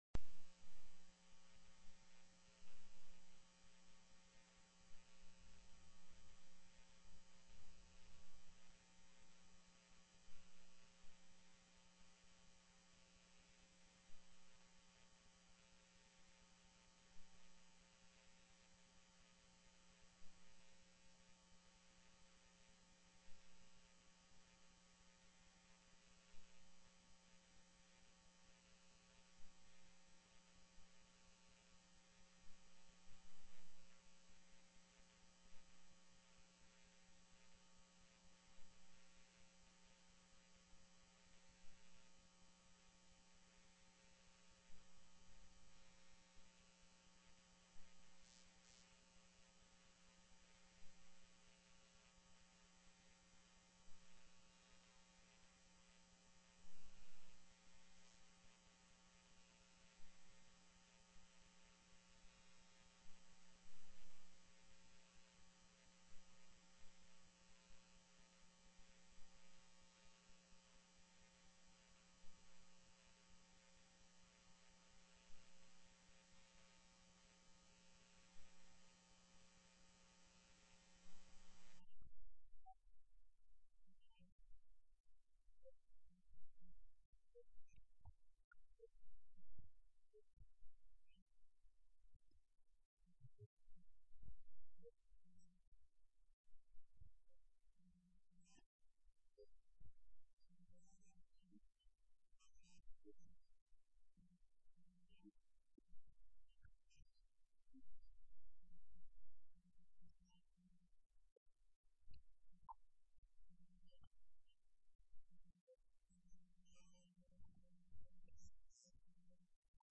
HB 226 NAMING VETERANS' WAY IN MAT-SU TELECONFERENCED